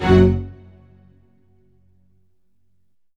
Orchestral Hits
ORCHHIT F07L.wav